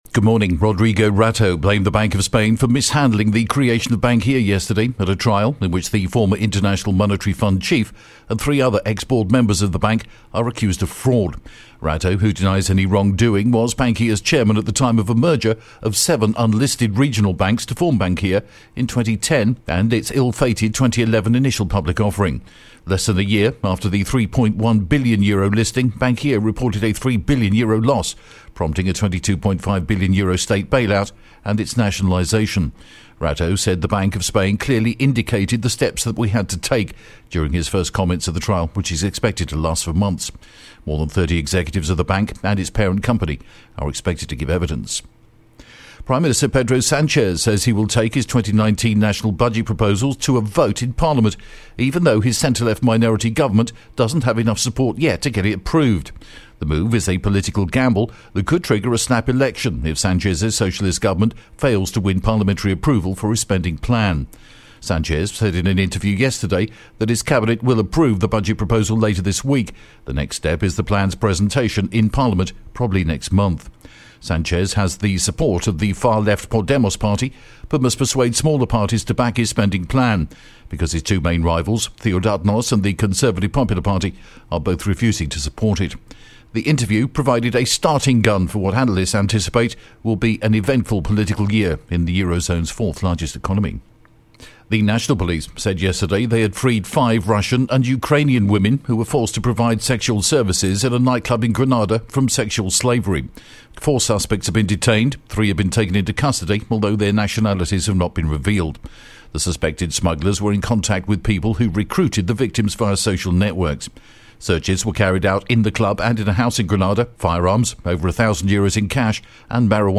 The latest Spanish News Headlines in English: January 9th